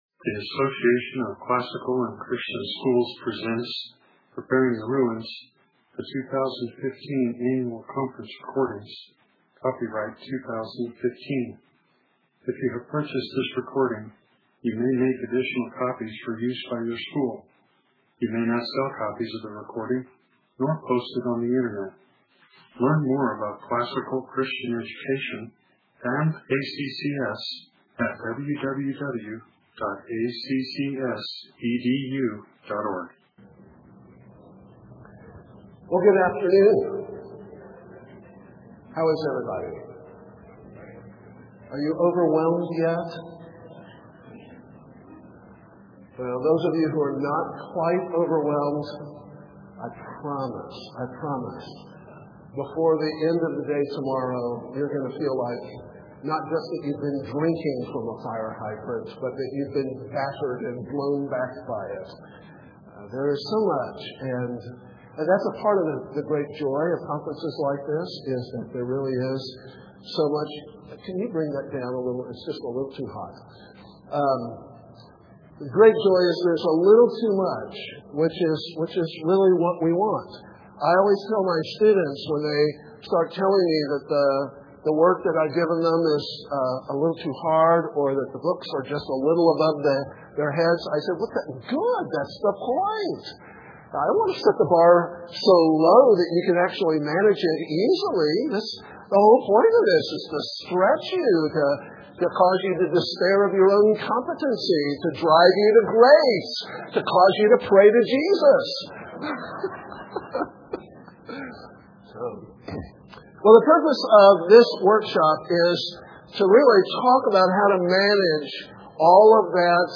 2015 Workshop Talk, 1:00:46, All Grade Levels, Culture & Faith, General Classroom
Additional Materials The Association of Classical & Christian Schools presents Repairing the Ruins, the ACCS annual conference, copyright ACCS.